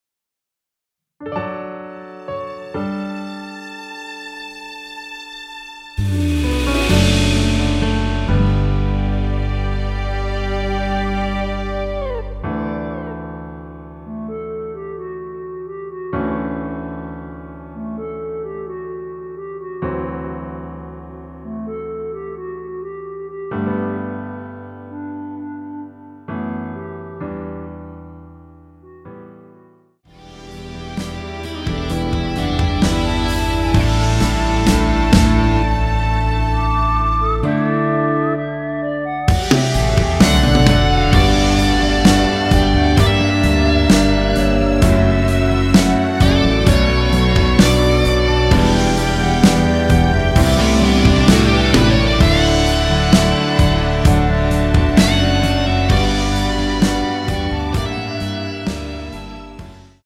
원키에서(-1)내린 멜로디 포함된 MR입니다.
앞부분30초, 뒷부분30초씩 편집해서 올려 드리고 있습니다.
곡명 옆 (-1)은 반음 내림, (+1)은 반음 올림 입니다.
(멜로디 MR)은 가이드 멜로디가 포함된 MR 입니다.